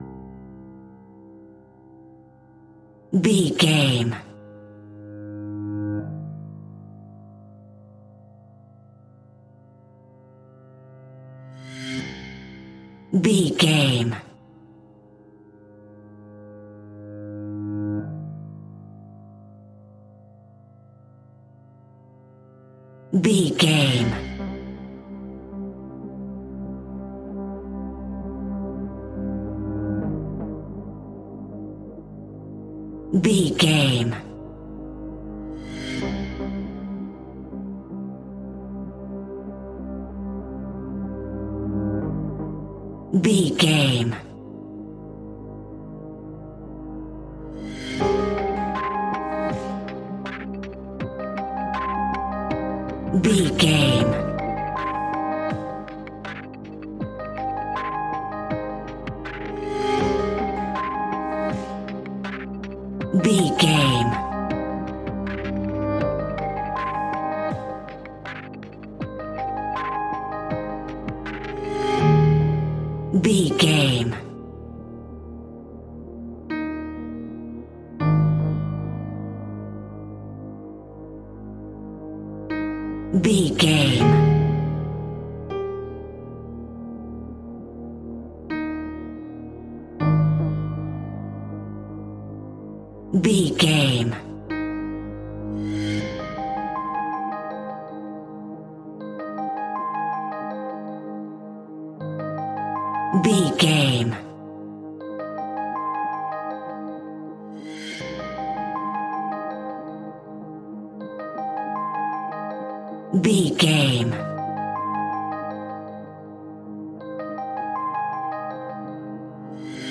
Aeolian/Minor
Slow
scary
ominous
dark
haunting
eerie
melancholy
ethereal
synthesiser
piano
strings
cello
percussion
horror music
horror instrumentals